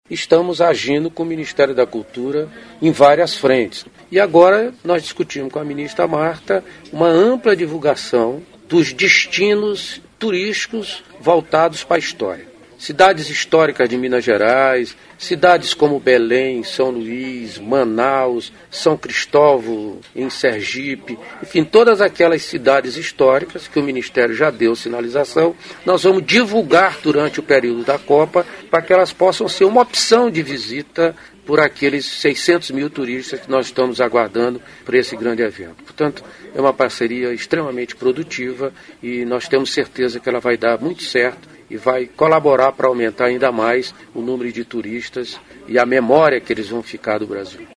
aqui para ouvir declaração do ministro Gastão Vieira sobre benefícios da associação entre turismo e cultura.